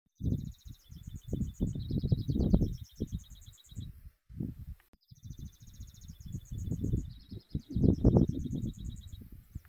Pampas Pipit (Anthus chacoensis)
Life Stage: Adult
Condition: Wild
Certainty: Recorded vocal